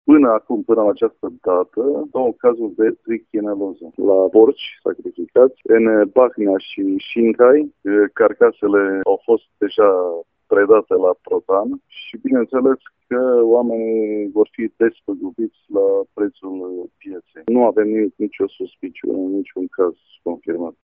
Directorul DSV Mureș, Vasile Oprea: